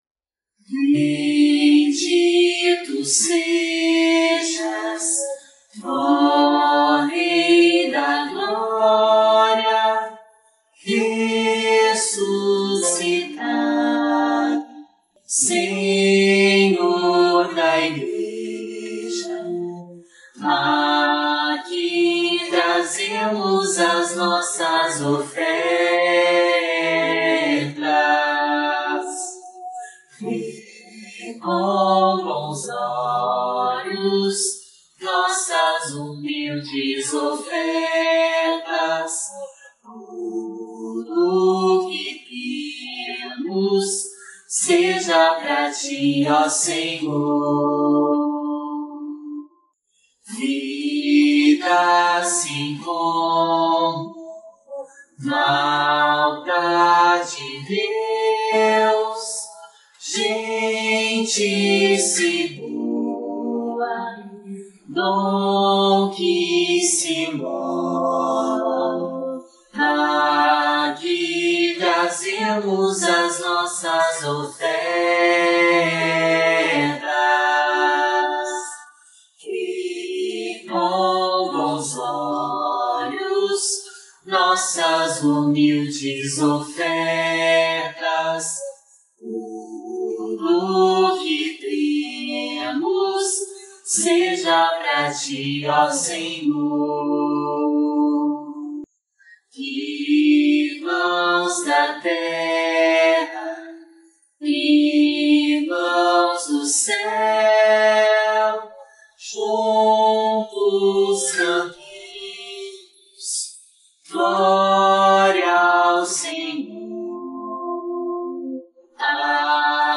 Notas: Separei vozes dos instrumentos, mas desta vez não ficou tão bom.